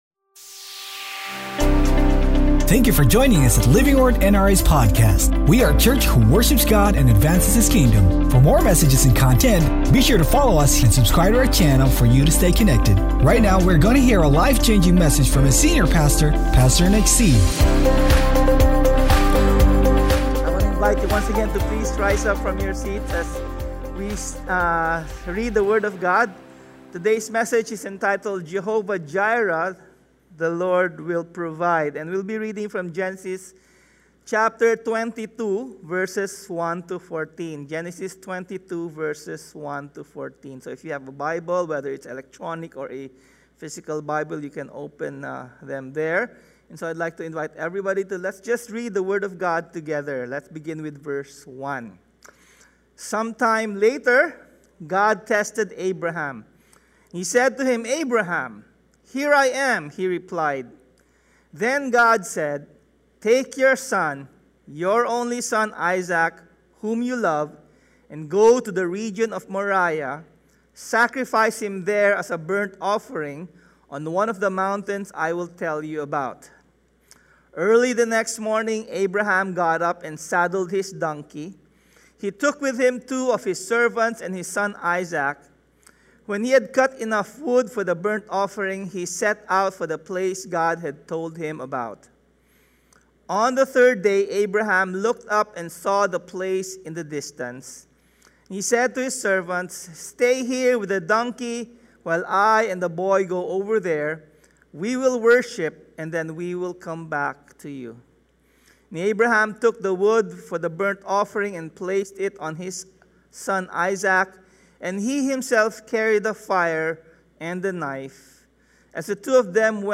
When God says “let go”, it’s always because He promised He will provide. Sermon Title: JEHOVAH JIREH: THE LORD WILL PROVIDE Scripture Text: GENESIS 22 Sermon Series: Abraham: Journey of Faith Sermon Notes: GENESIS 22:1-14 NIV 1 Some time later God tested Abraham.